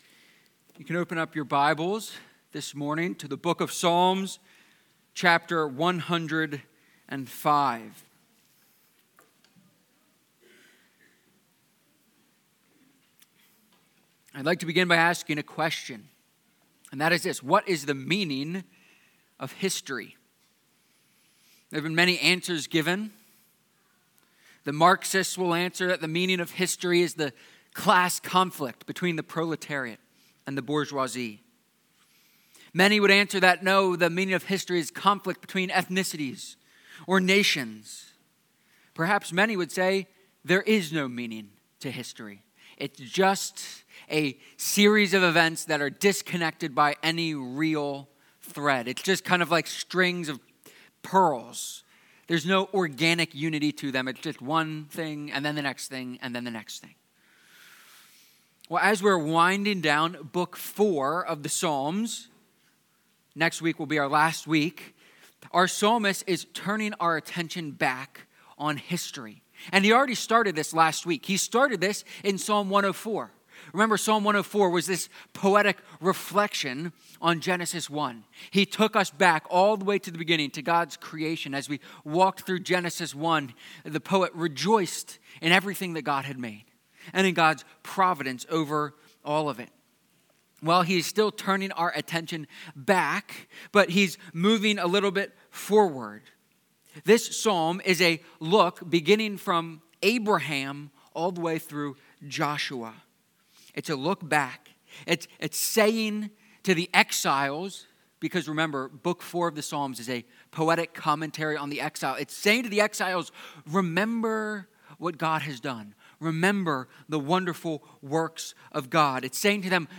Psalm-105-sermon.mp3